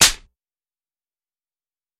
На этой странице собраны разнообразные звуки шлепков — от легких хлопков до сочных ударов.
Звук оплеухи